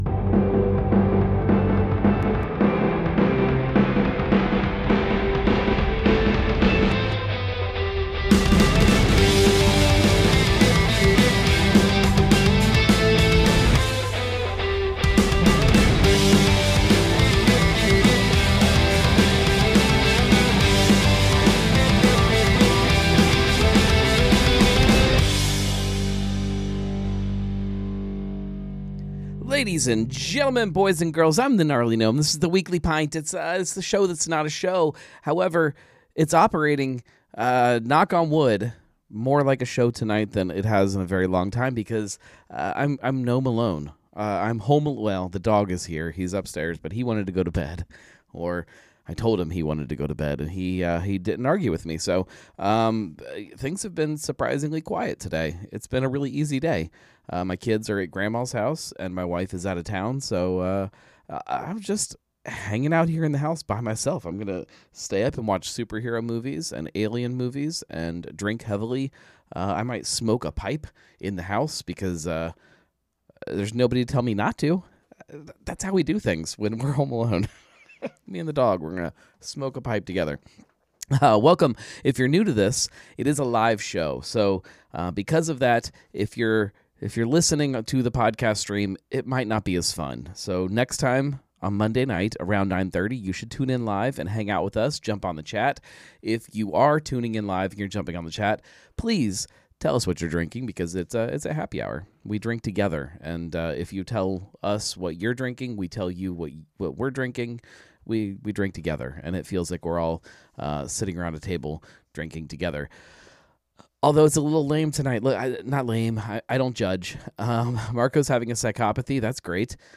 I’m home alone… well… I have the Gnome-dog, but things are surprisingly quiet over here.